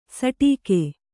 ♪ saṭīke